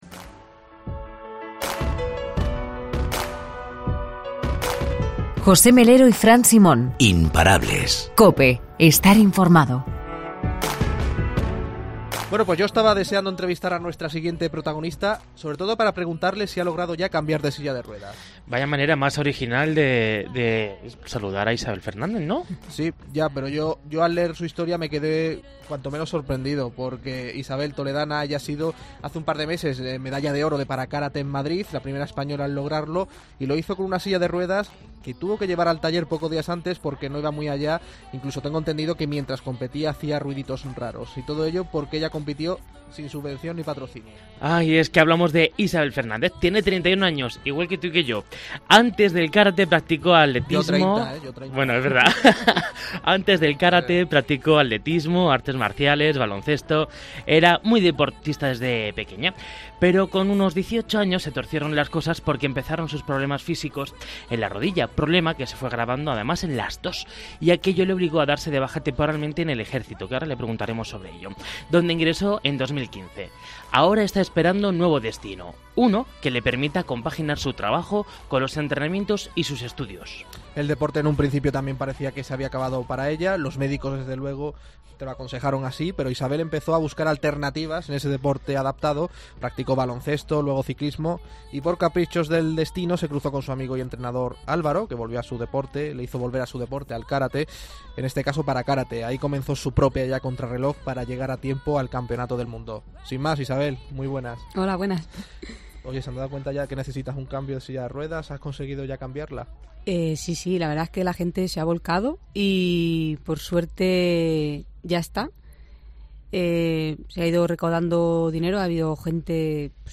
'Imparables' tenía mucho interés en entrevistar